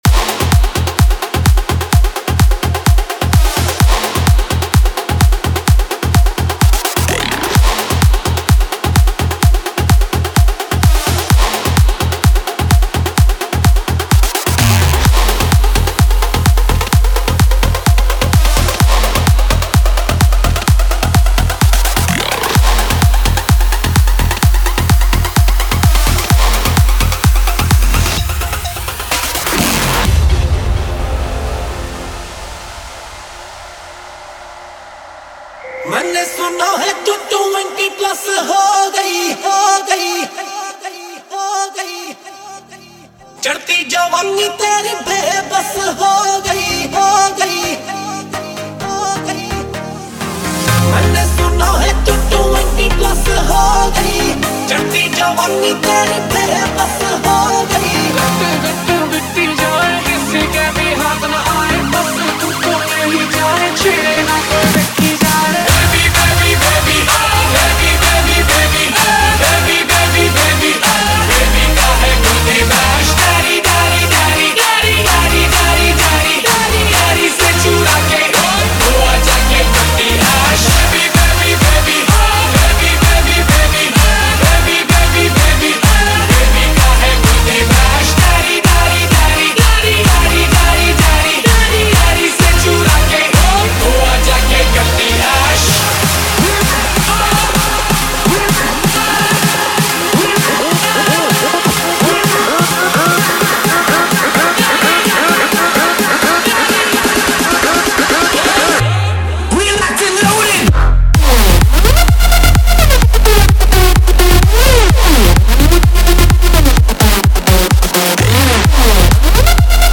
Category: Latest Dj Remix Song